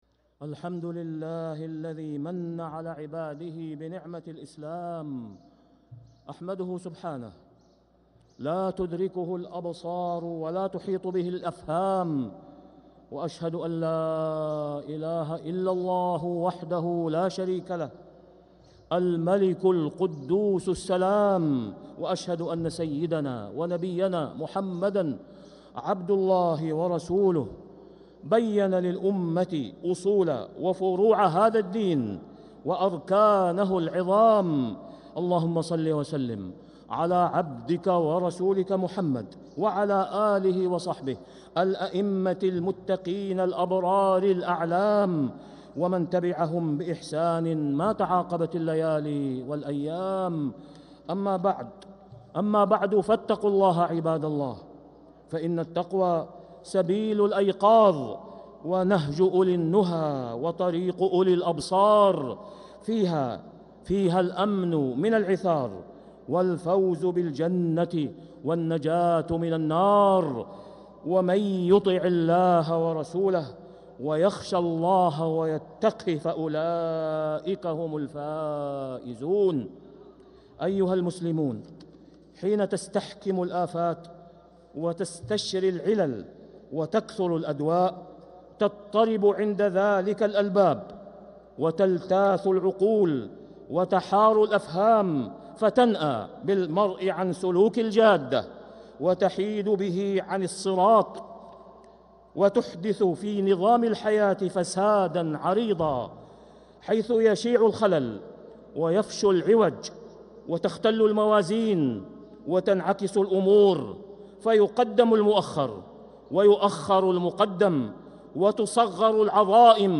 خطبة الجمعة 3-7-1446هـ | Khutbah Jumu’ah 3-1-2025 > خطب الحرم المكي عام 1446 🕋 > خطب الحرم المكي 🕋 > المزيد - تلاوات الحرمين